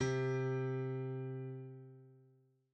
ITA Piano C2.wav